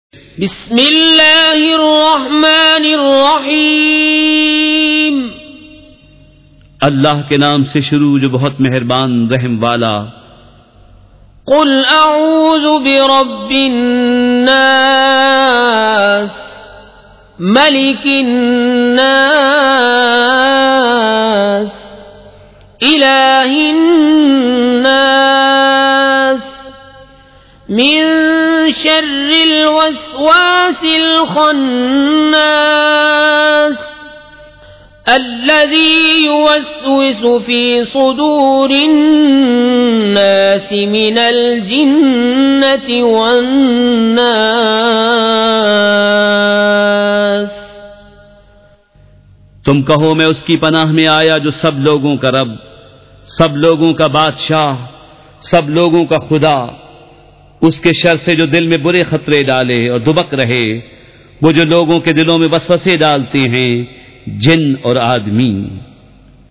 سورۃ الناس مع ترجمہ کنزالایمان ZiaeTaiba Audio میڈیا کی معلومات نام سورۃ الناس مع ترجمہ کنزالایمان موضوع تلاوت آواز دیگر زبان عربی کل نتائج 3524 قسم آڈیو ڈاؤن لوڈ MP 3 ڈاؤن لوڈ MP 4 متعلقہ تجویزوآراء